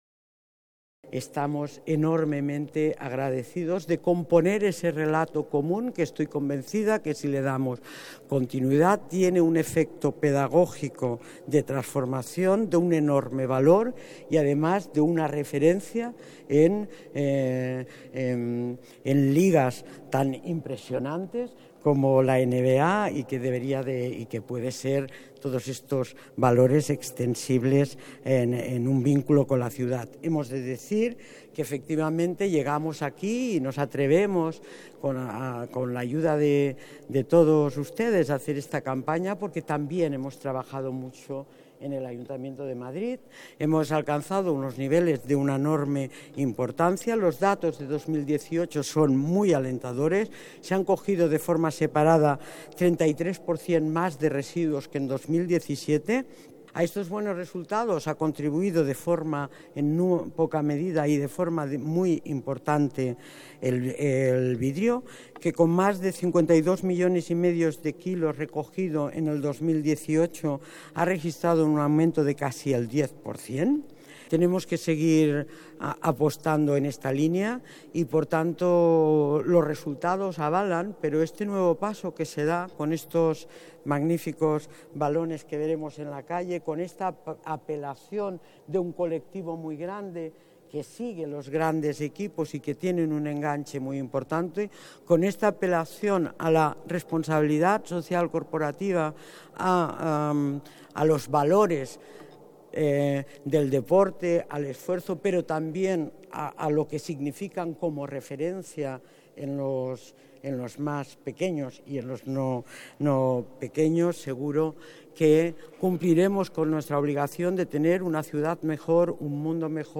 Inés Sabanés, delegada del Área de Medio Ambiente y Movilidad, habla sobre la Campaña de Reciclaje: